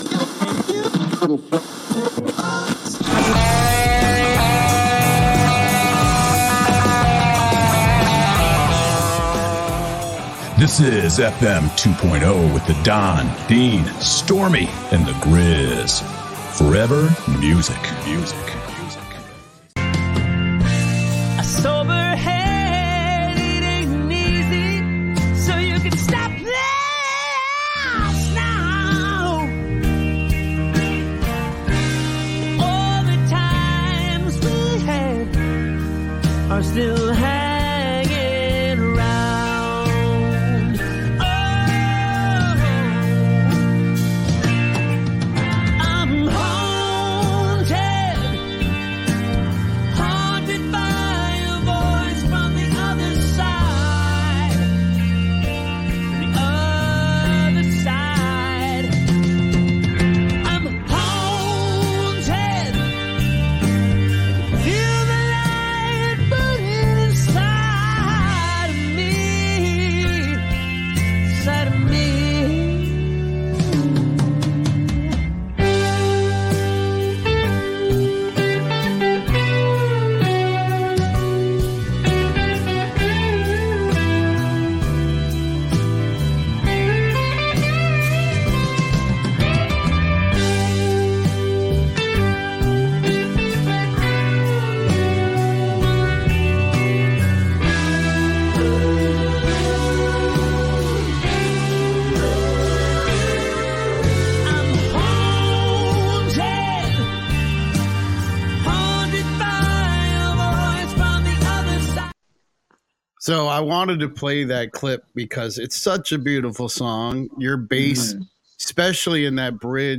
Rudy joined us for another visit and we dug into the scene of the Sunset Strip when the influx of New Wave and Punk invaded Metal territory. We discuss the impact of Eddie Van Halen and the impact Quiet Riot/Randy Rhodes had on the Strip in more details. We talked about the fashion of the time.